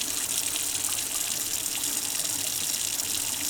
TAP_Kitchen_Water_Tap_Running_loop_mono.wav